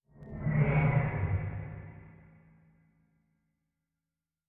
Distant Ship Pass By 1_1.wav